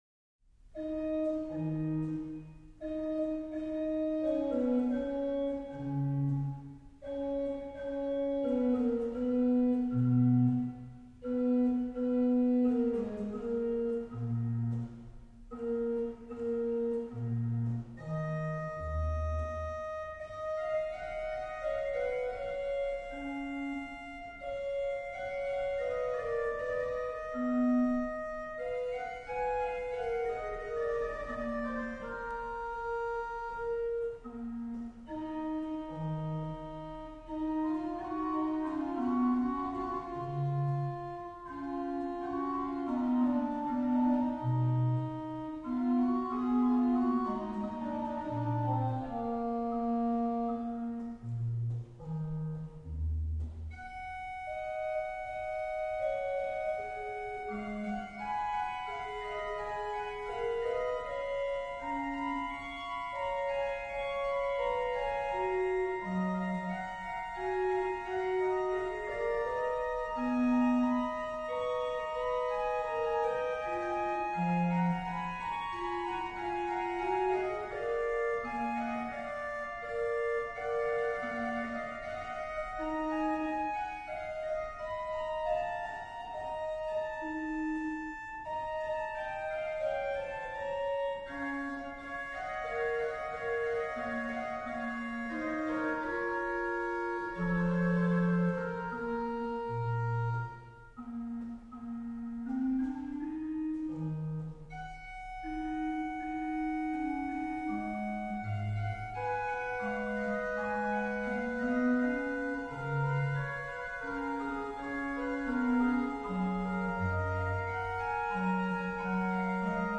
m. 5: rh: BW: Nacht4, Gms 4 (8ve lower)